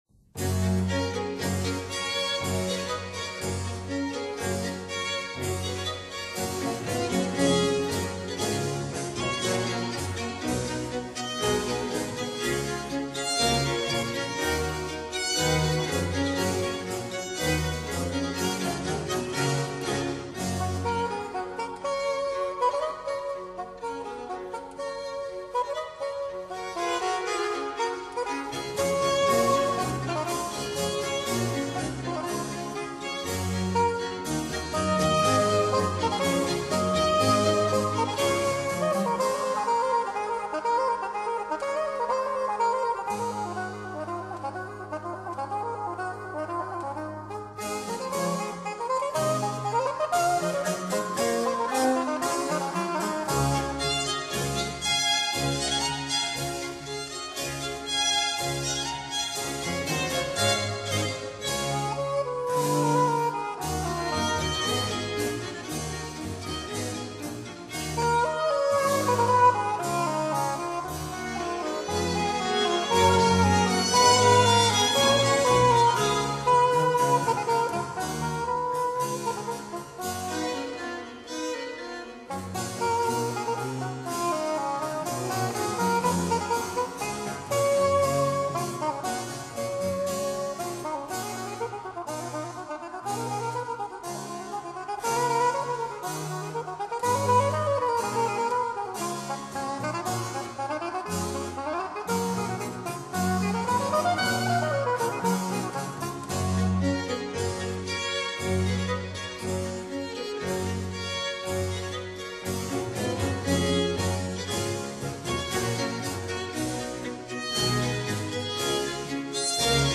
Allegro    [0:05:01.67]